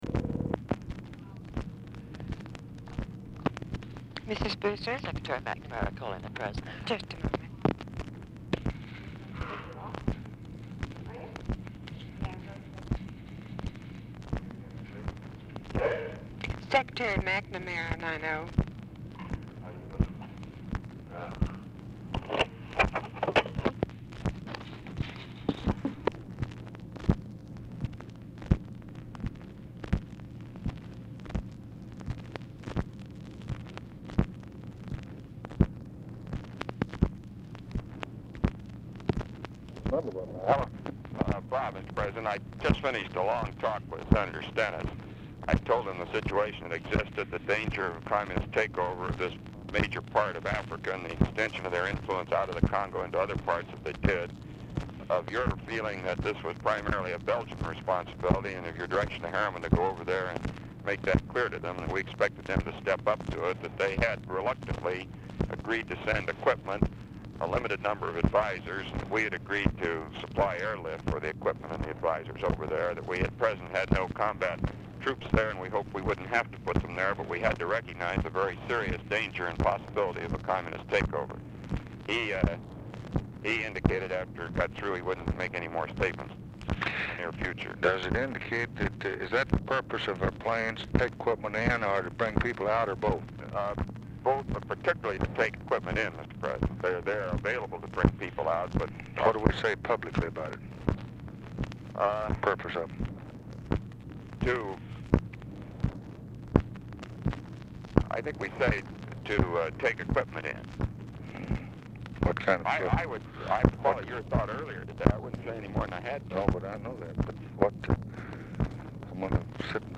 Telephone conversation # 4948, sound recording, LBJ and ROBERT MCNAMARA, 8/15/1964, 12:15PM | Discover LBJ
Format Dictation belt
Location Of Speaker 1 Oval Office or unknown location
Specific Item Type Telephone conversation